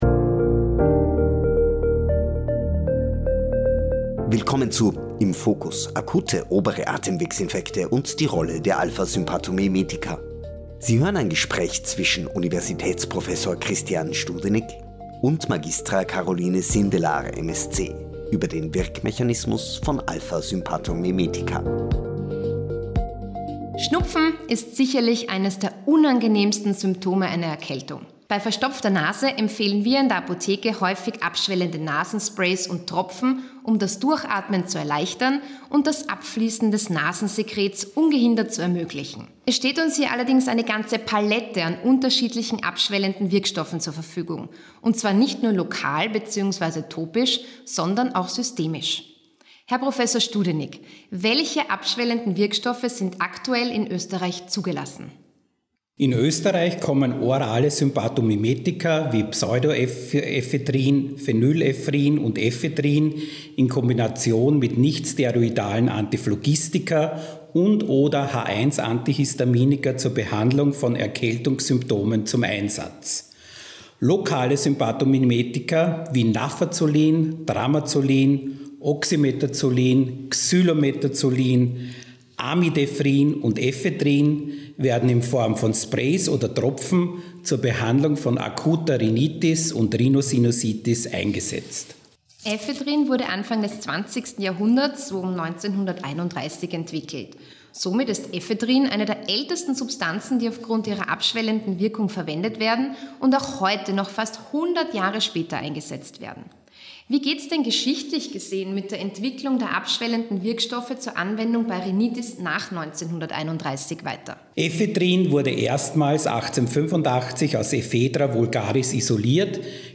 Sie hören ein Gespräch